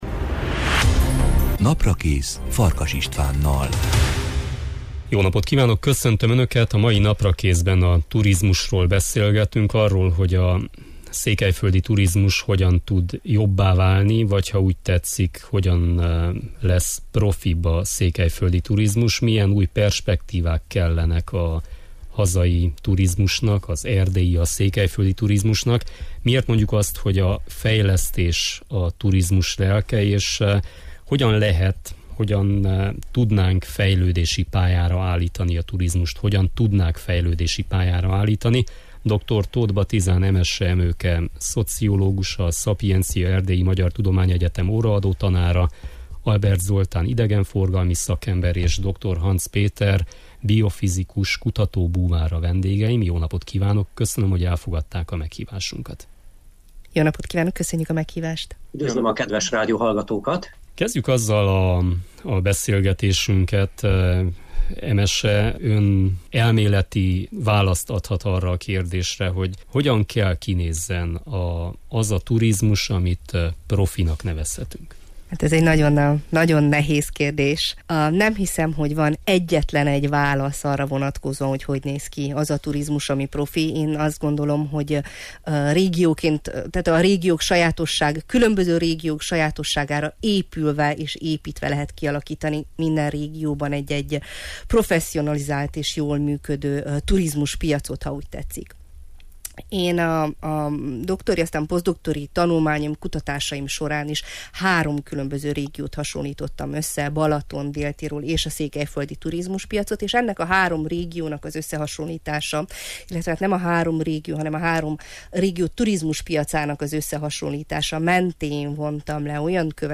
A mai Naprakészben a turizmusról beszélgetünk, arról, hogy a székelyföldi turizmus hogyan tud jobbá válni, hogyan lesz profibb a székelyföldi turizmus. Milyen új perspektívák kellenek a hazai, az erdélyi, a székelyföldi turizmusnak. Miért mondjuk, hogy a fejlesztés a turizmus lelke és hogyan lehet fejlődési pályára állítani a turizmust.